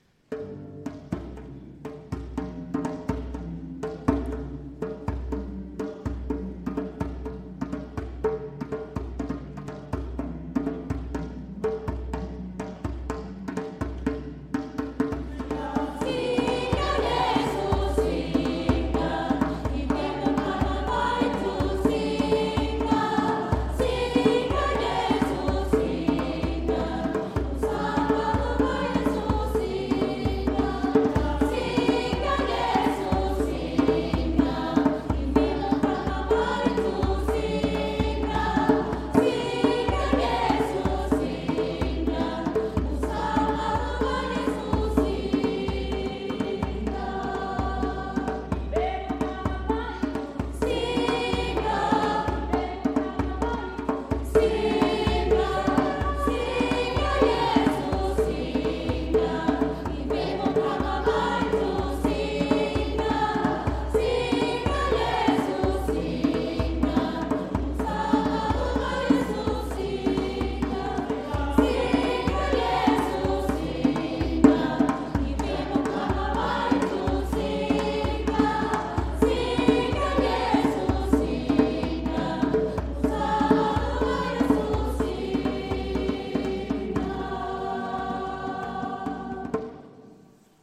Die afrikanischen Lieder aus dem Gottesdienst
Unser Chor begleitete diese Messe mit neuen afrikanischen Gesängen die den einen oder anderen Kirchenbesucher zum "mitshaken" animierten.